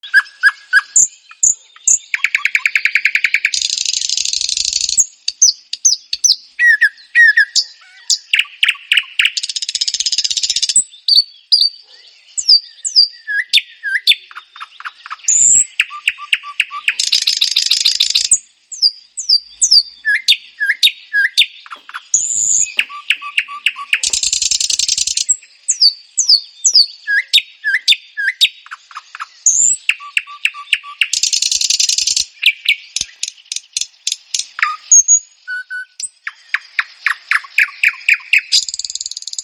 Privighetorile din Crăiasca (Luscinia megarhynchos și Luscinia luscinia)
• Sunt cele mai melodioase păsări din pădure, cu triluri bogate și curate.
• Cântă chiar și noaptea sau în zori, când alte păsări tac.
• Privighetoarea comună are triluri variate și cântă clar, ca o melodie de flaut.
• Privighetoarea de zăvoi cântă mai grav, dar la fel de frumos.
Bucură-te de concertul privighetorilor din Crăiască!
Privighetoare.m4a